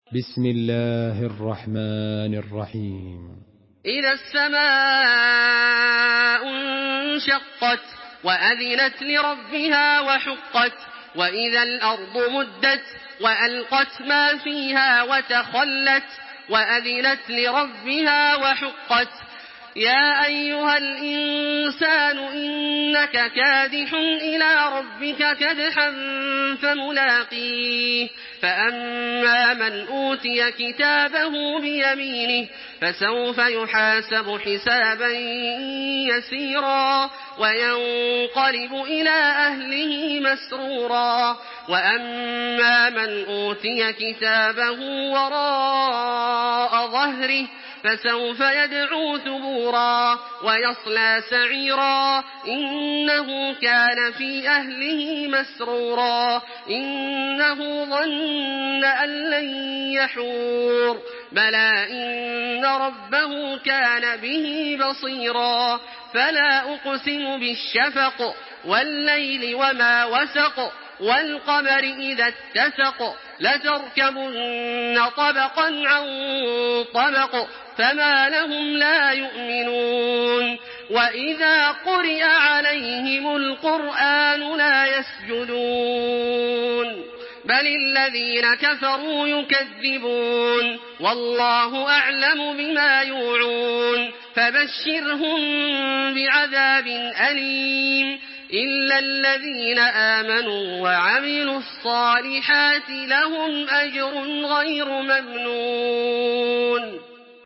Makkah Taraweeh 1426
Murattal Hafs An Asim